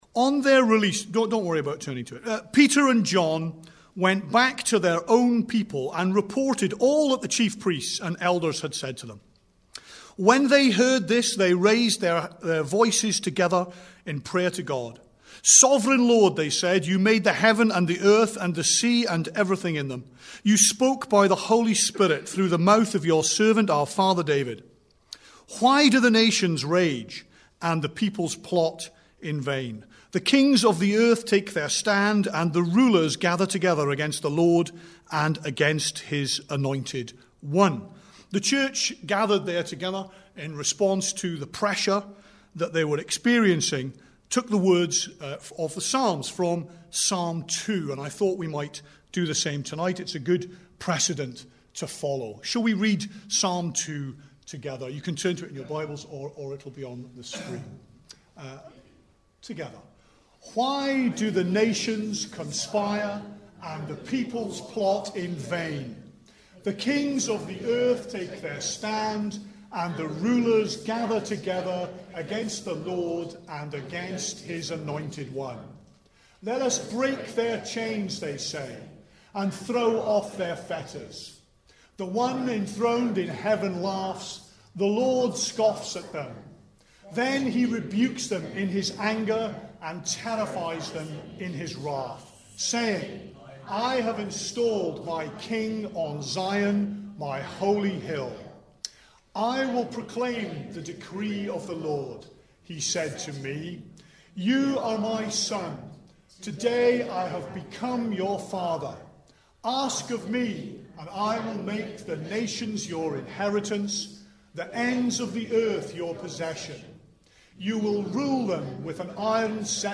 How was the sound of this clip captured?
Rom 5:14 Service Type: Sunday Evening Bible Text